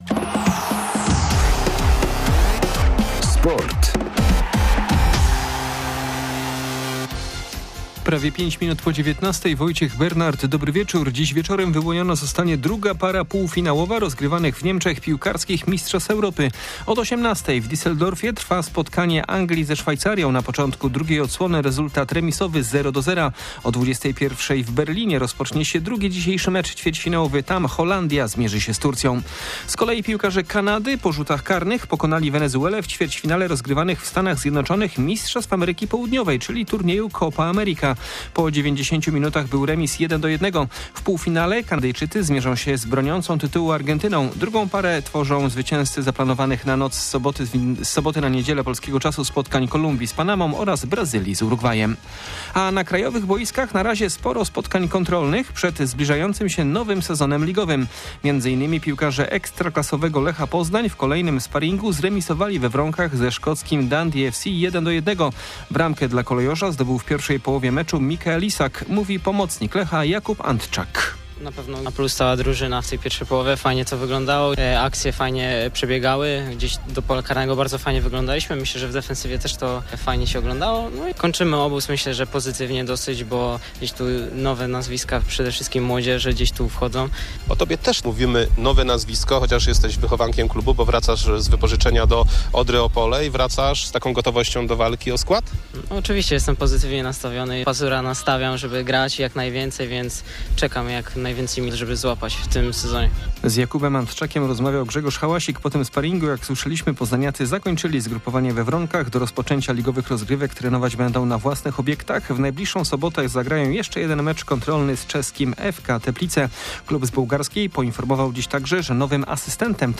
06.07.2024 SERWIS SPORTOWY GODZ. 19:05